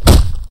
kick4.ogg